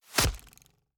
Free Fantasy SFX Pack
Bow Attacks Hits and Blocks
Bow Impact Hit 3.wav